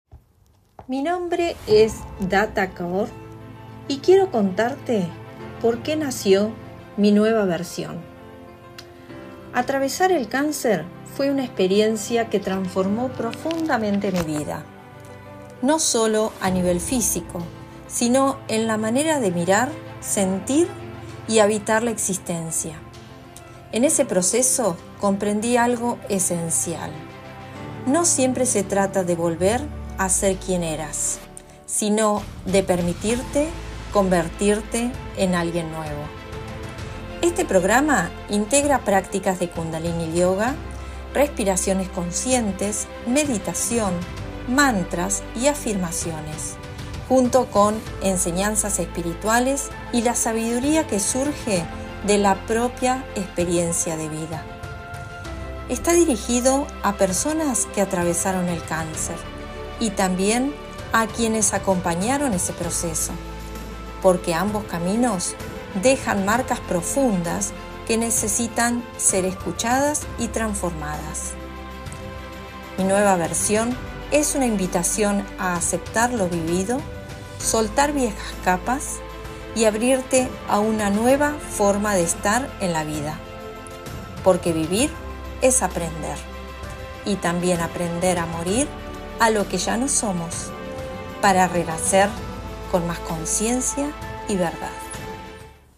ESCUCHA MI VOZ
KUNDALINI-yoga-_-meditacionmp3.mp3